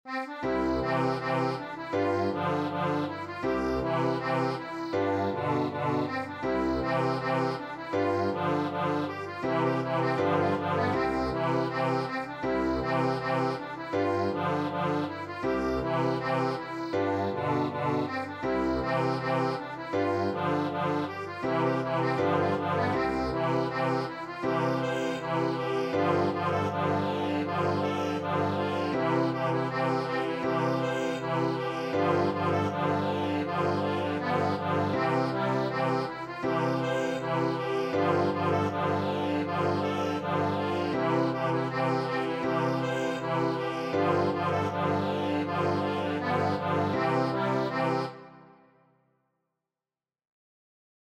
Dieser Schottisch ist eine Patchwork-Komposition: Die Takte 1 bis 3 und 6+7 entstammen dem Lied Vetter Michel , und Takt 8+9 dem Schluss von "Soldiers Joy" (in der Fiddle-Fassung, nicht in der Konzertina-Tabulatur).
;-) Scan der Originalseite Speziell für Deutsche CG-Konzertina: Tabulatur: (62 KB) (800 KB)